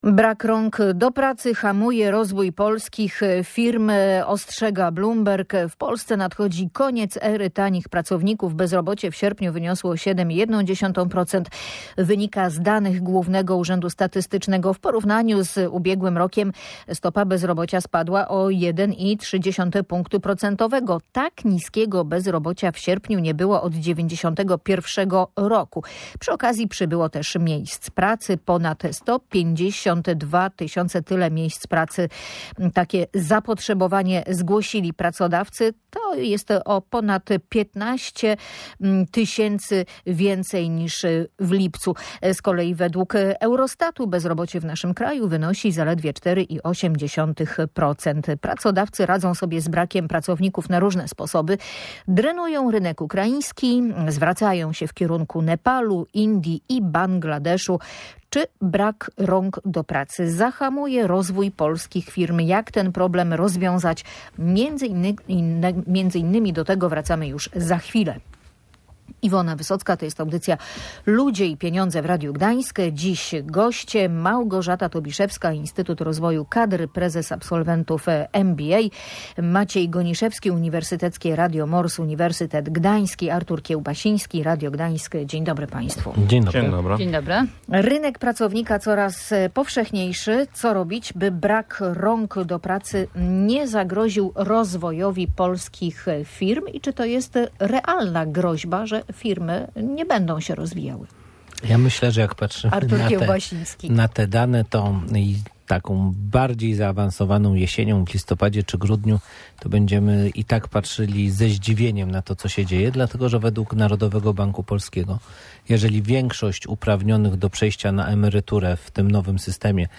O tym rozmawiali eksperci audycji Ludzie i Pieniądze.